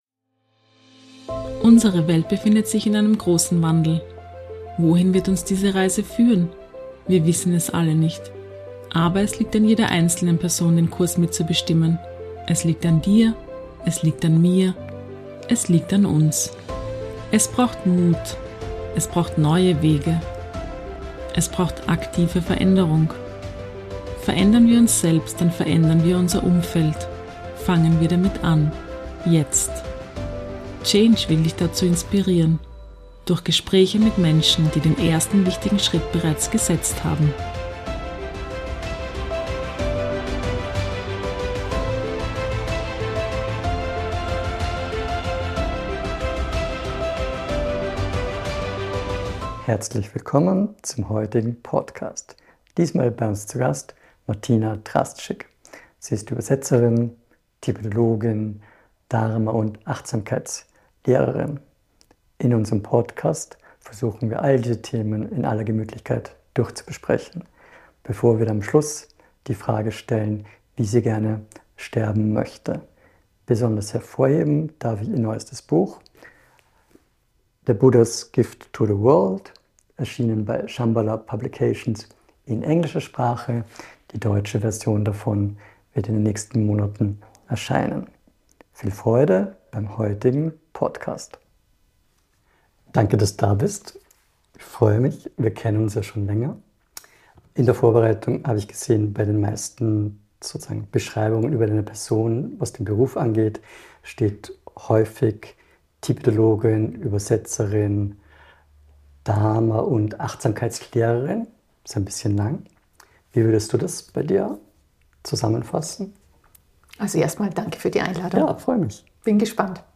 Somit war schon einmal ein gemeinsamer Ausgangspunkt für dieses Gespräch gegeben, das sich jedoch sehr inspirierend und praxisnah am Alltag und am Leben an sich orientiert.